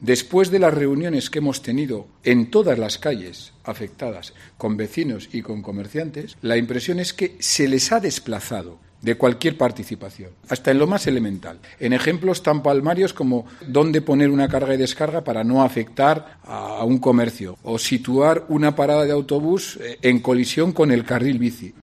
Conrado Escobar, portavoz del PP de Logroño